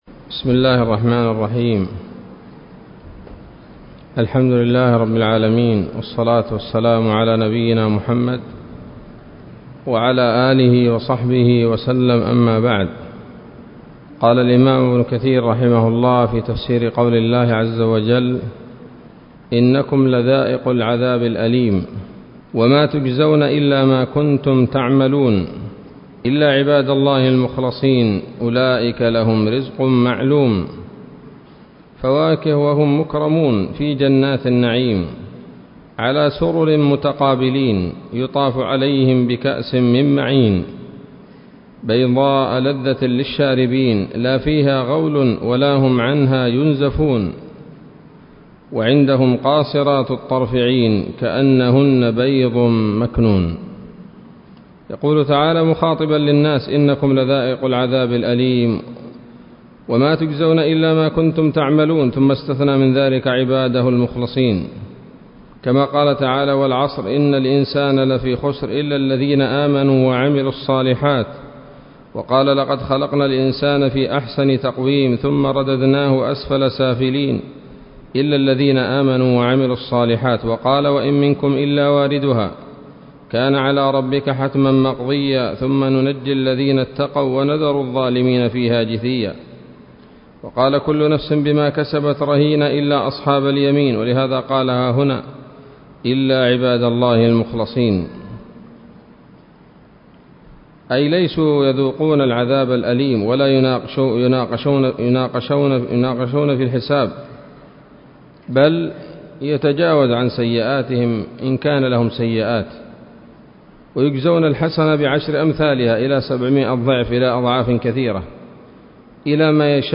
الدرس الرابع من سورة الصافات من تفسير ابن كثير رحمه الله تعالى